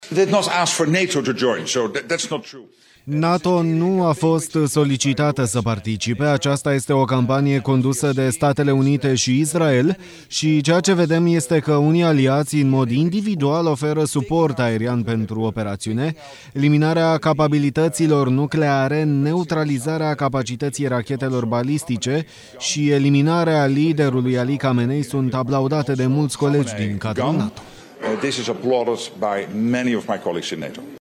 Secretarul general al Alianței, Mark Rutte: „NATO nu a fost solicitată să participe”
03mar-17-Rutte-NATO-nu-e-parte-din-conflictul-din-Iran-TRADUS.mp3